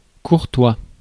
Hier die korrekte Aussprache lt. Wiktionary:
fr-courtois.mp3